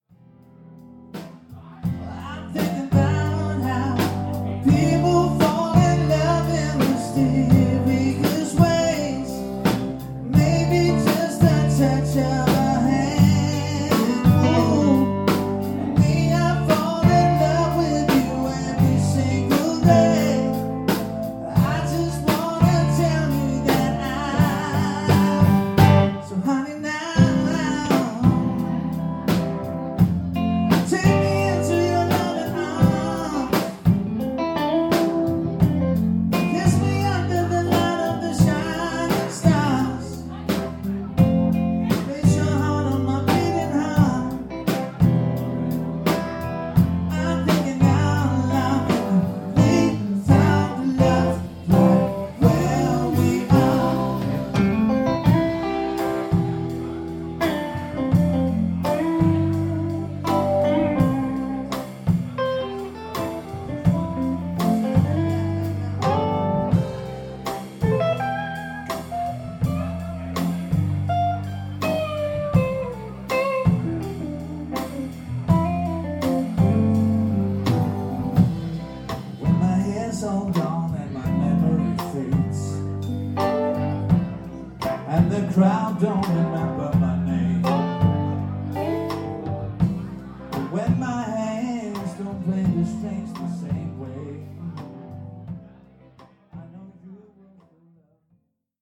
sang og guitar
keyboards
trommer